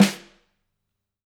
snare2.mp3